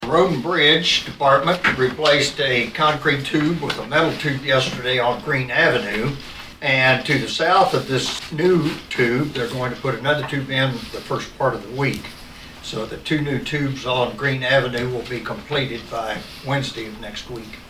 During the meeting of the Saline County Commission on Thursday, September 13, Southern District Commissioner Monte Fenner gave an update on some roadwork.